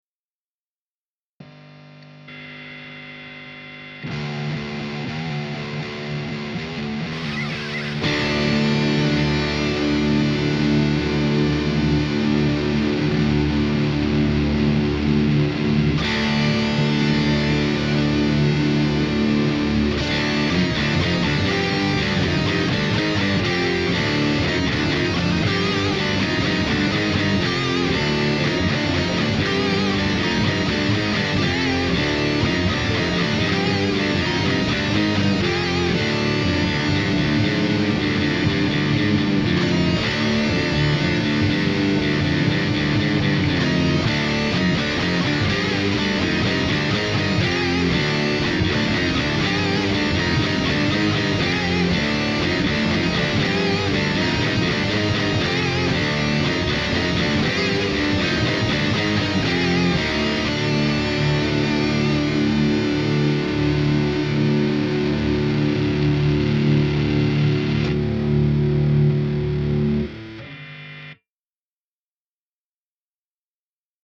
Allerdings haben diese Amps wesentlich mehr Gain.
Klangbeispiele aus dem Softube Amp Room Bundle (Metal Room)
Beispiel aus dem Metal Amp Room
Diese Amps hier klingen durchweg sehr dynamisch und lebendig.
Amp-Room-Metal_example.mp3